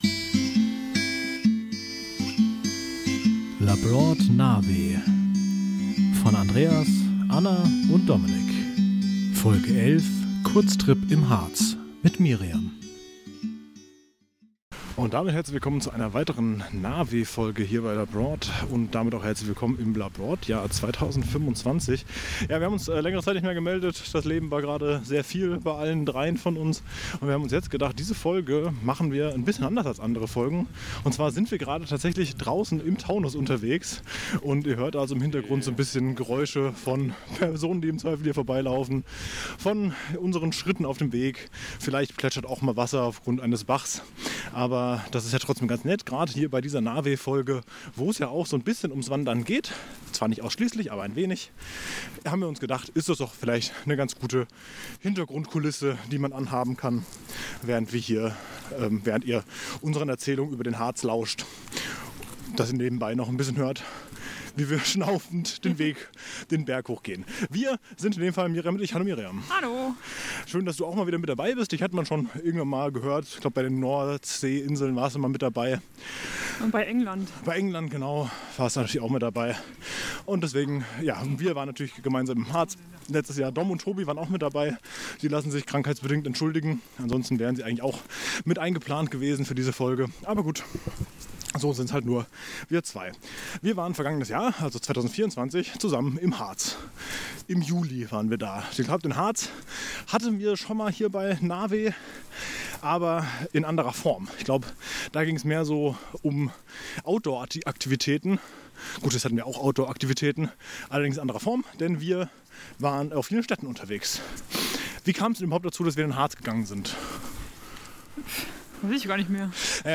Das führt zu mehr Nahweh-Feeling durch nette Hintergrundgeräusche, haben wir uns gedacht.